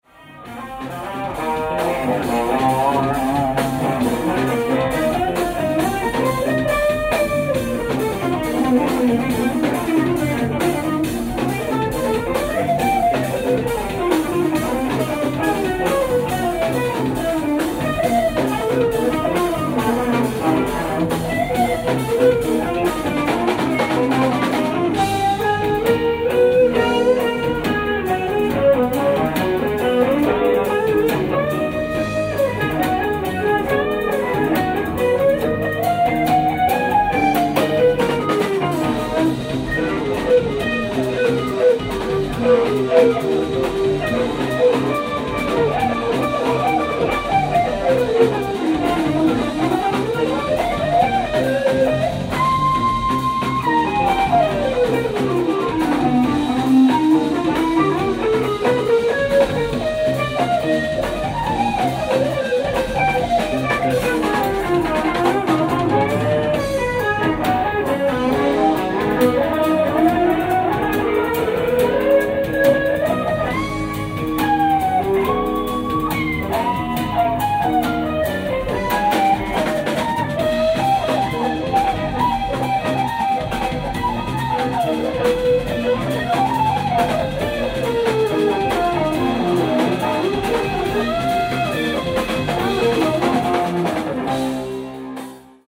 ライブ・アット・ホット・ジャズクラブ、ミュンスター、ドイツ 10/29/2024
※試聴用に実際より音質を落としています。